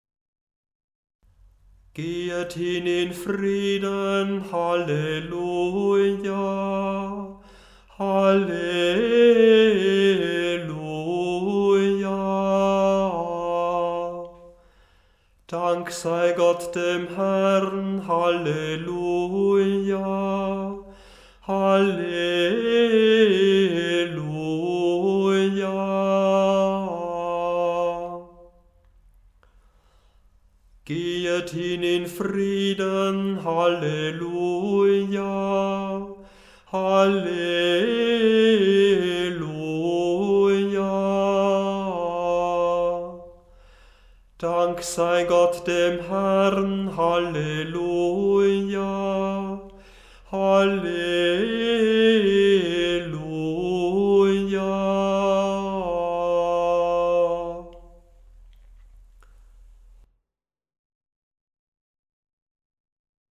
Die Gesänge sind in der Tonhöhe absteigend geordnet, zuerst deutsch, danach latein
gehet-hin-in-frieden-halleluja_gl-591-6_f1.mp3